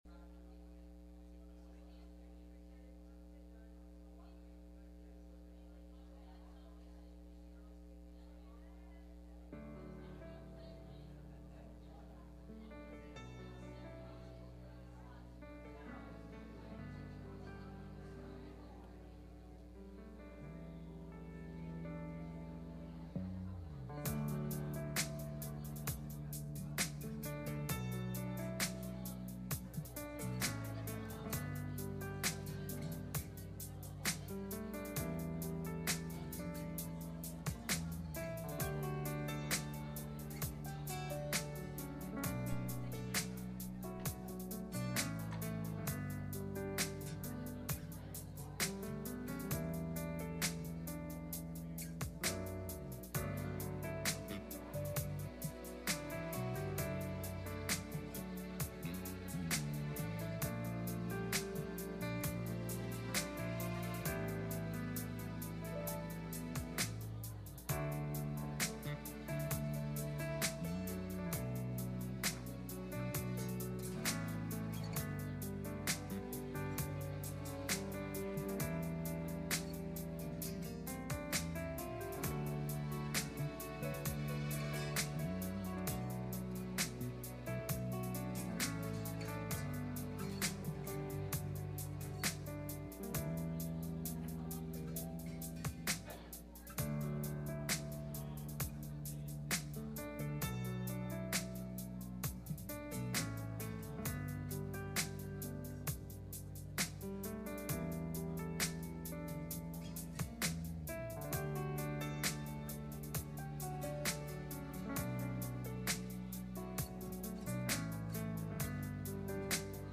2 Kings 6:8-23 Service Type: Sunday Morning « The Life Of Jesus